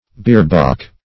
Search Result for " bierbalk" : The Collaborative International Dictionary of English v.0.48: Bierbalk \Bier"balk`\ (b[=e]r"b[add]k`), n. [See Bier , and Balk , n.]